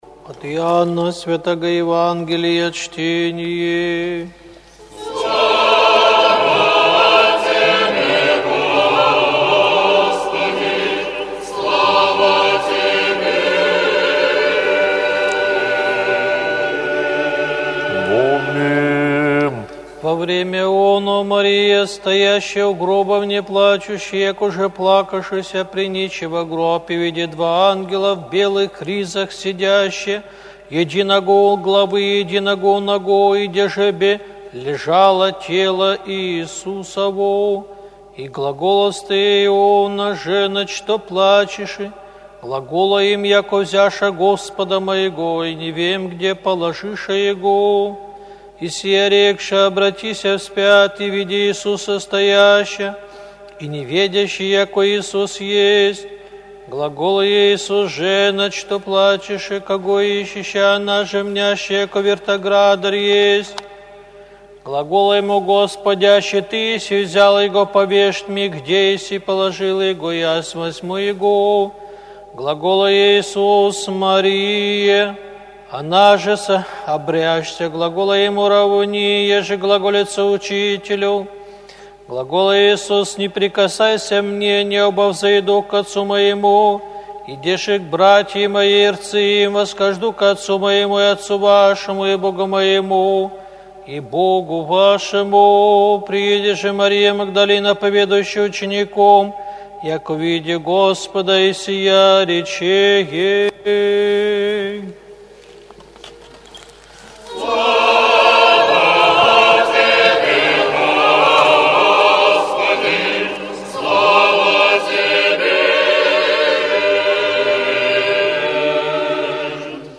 ЕВАНГЕЛЬСКОЕ ЧТЕНИЕ НА УТРЕНЕ